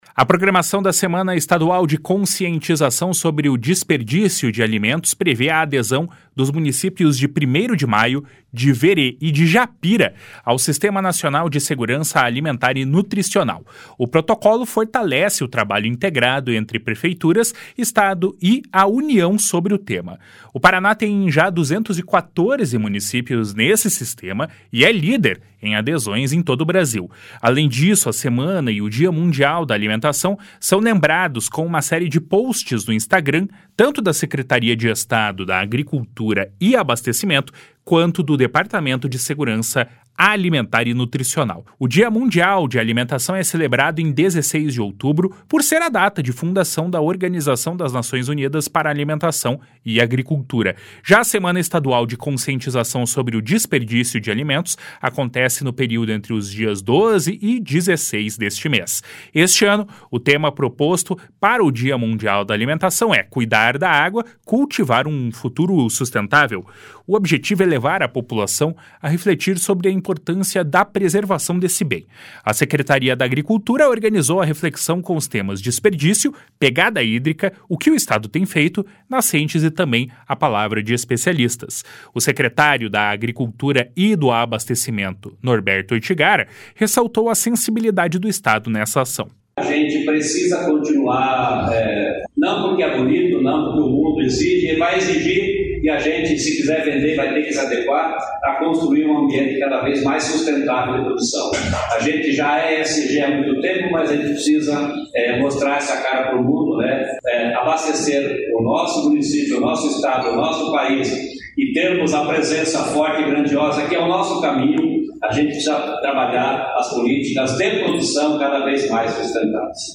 O secretário da Agricultura e do Abastecimento, Norberto Ortigara, ressaltou a sensibilidade do Estado nessa ação. // SONORA NORBERTO ORTIGARA //